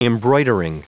Prononciation du mot embroidering en anglais (fichier audio)
Prononciation du mot : embroidering